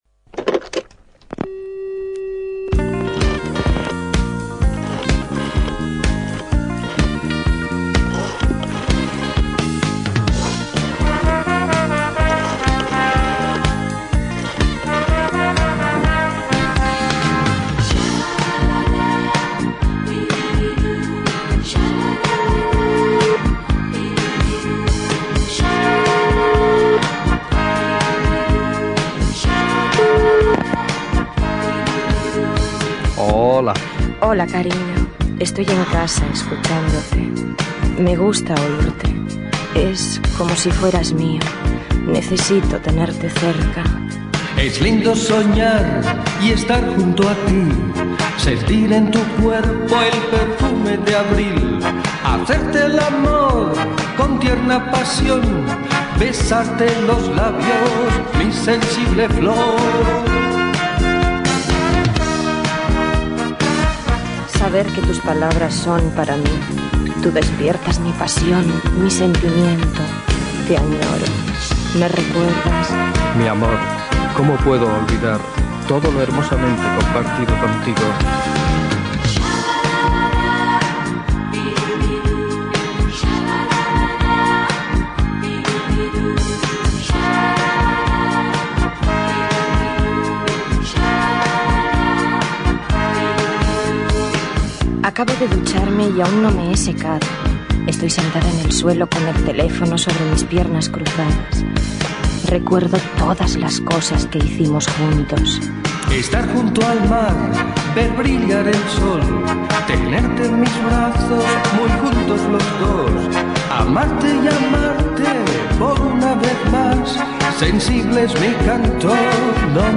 amb la trucada d'una oïdora
Musical